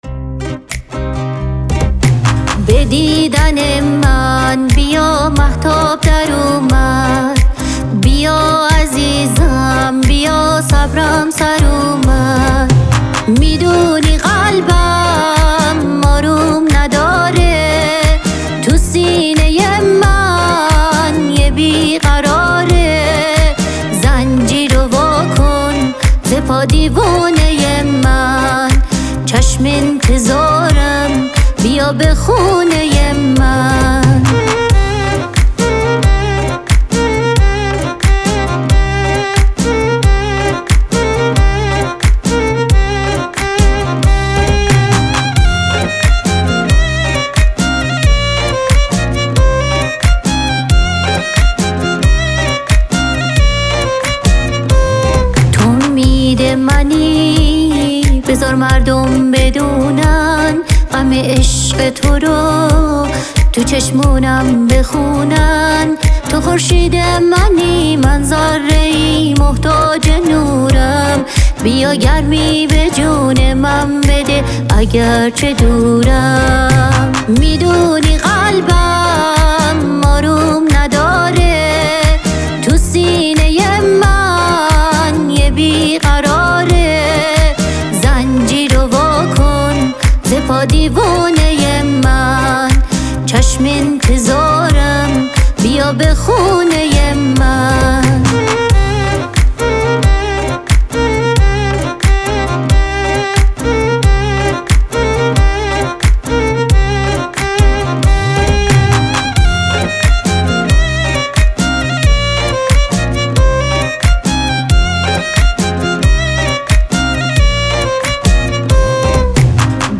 ترانه سرا و خواننده ایرانی
پاپ
متن ترانه بازخوانی